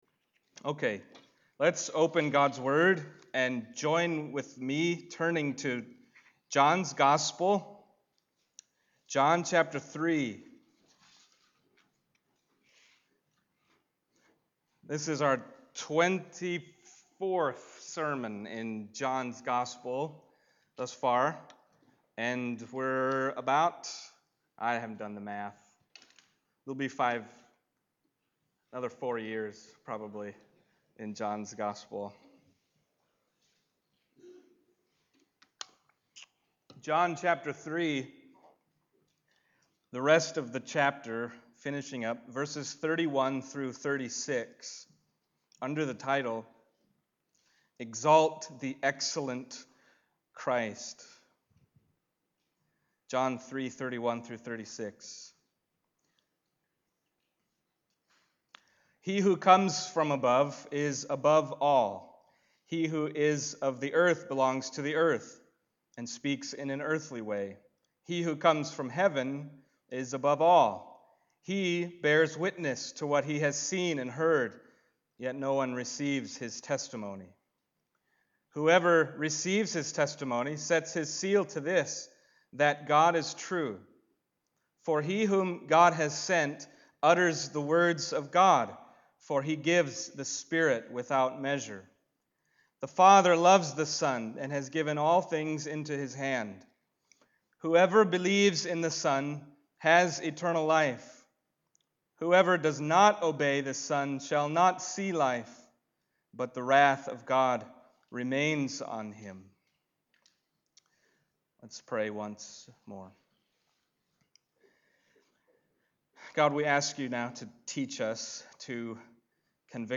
John Passage: John 3:31-36 Service Type: Sunday Morning John 3:31-36 « He Must Increase